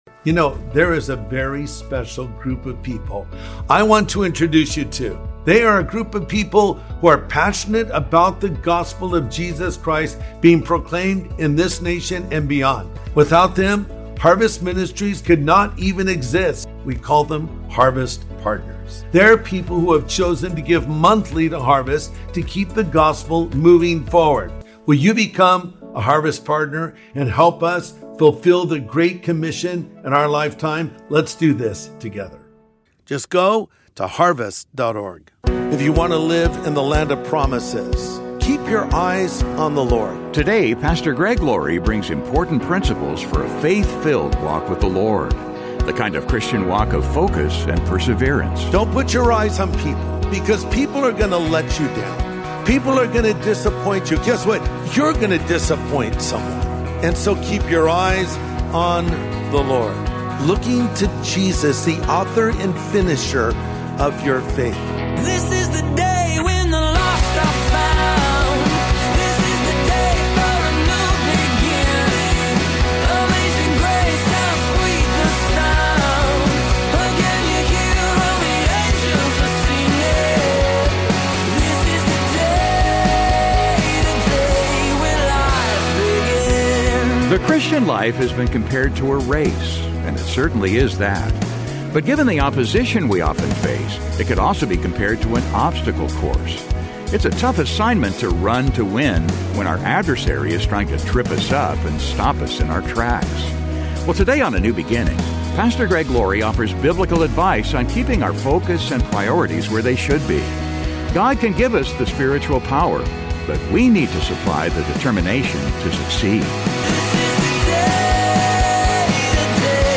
Well today on A NEW BEGINNING, Pastor Greg Laurie offers biblical advice on keeping our focus and priorities where they should be. God can give us the spiritual power, but we need supply the determination to succeed.